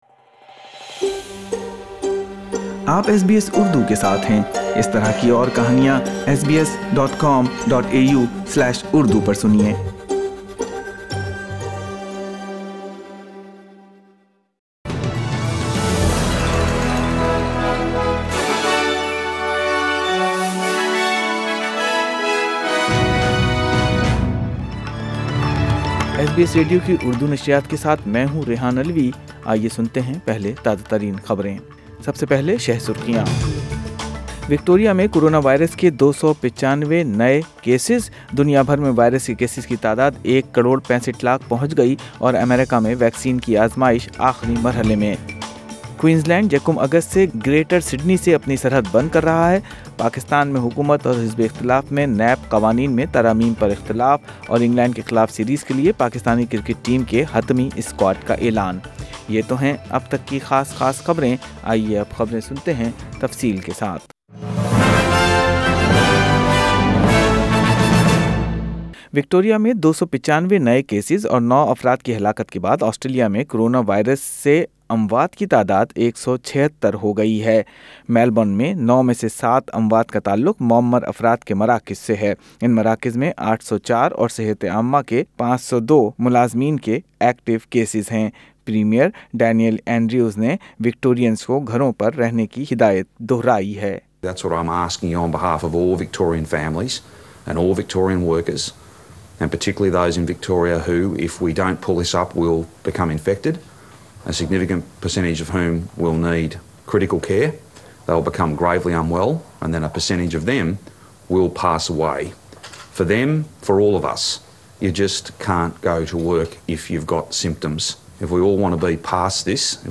اردو خبریں 29 جولائی 2020